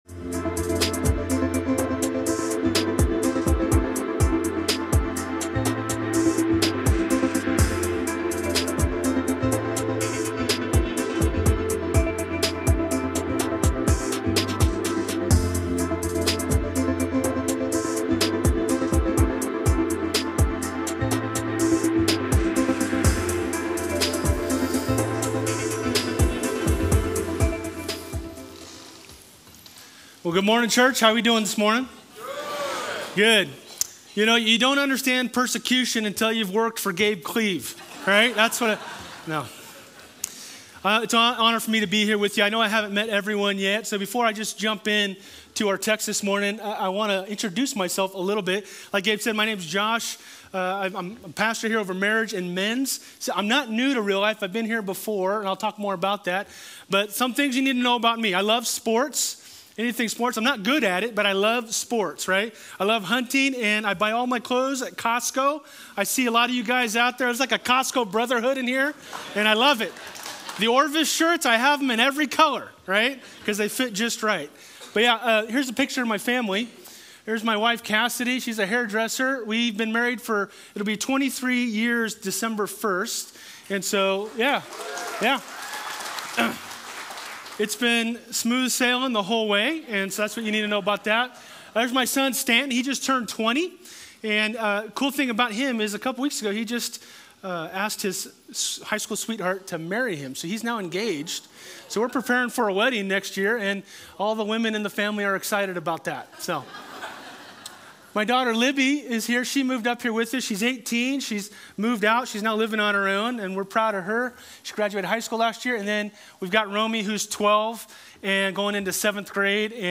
Other Sermon